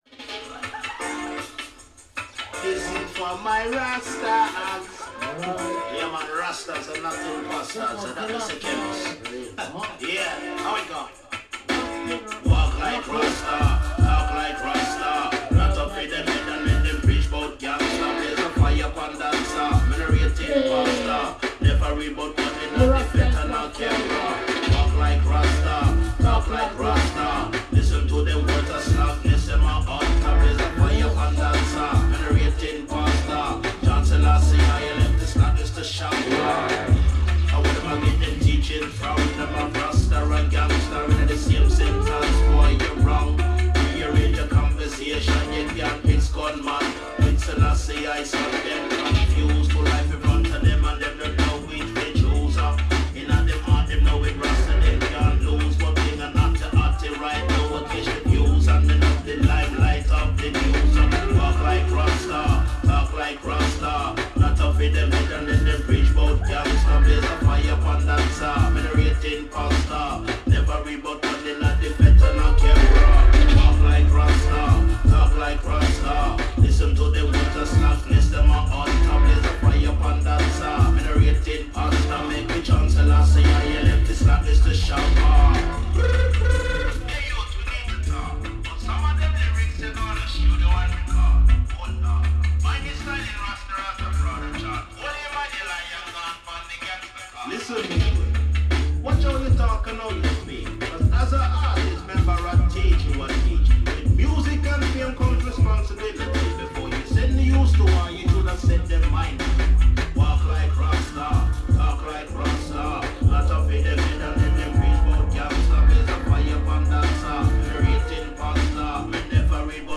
This is a 1hr live recording from the dance.
bass-driven, upful, high-energy set